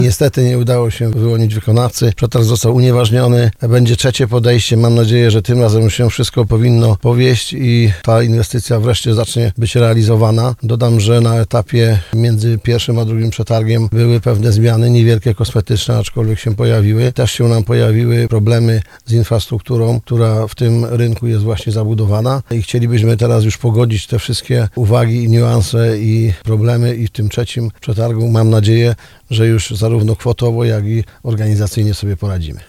Burmistrz Krzysztof Kaczmarski, który był gościem audycji Słowo za Słowo zapowiedział, że w myśl powiedzenia ‘do trzech razy sztuka’, urząd miejski nie poddaje się i szykuje się do ogłoszenia trzeciego przetargu.